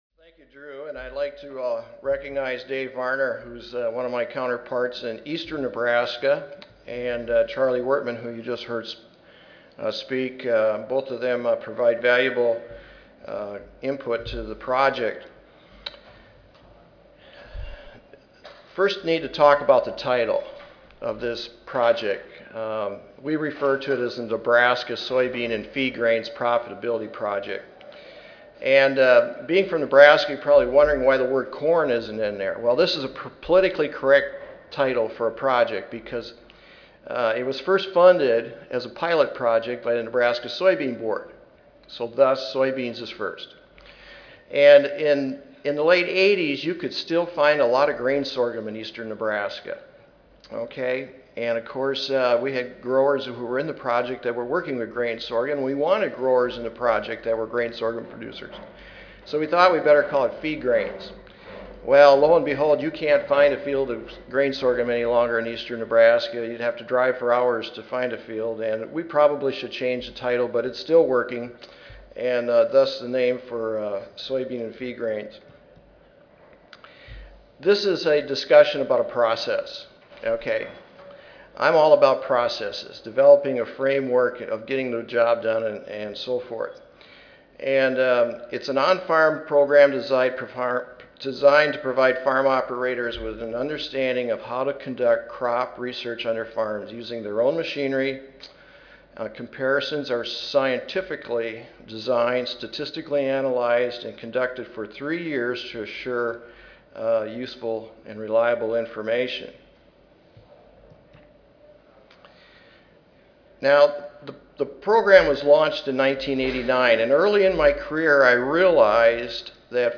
Abstract: The Soybean & Feed Grains Profitability Project: 20 Years of Grower Research. (2010 Annual Meeting (Oct. 31 - Nov. 3, 2010))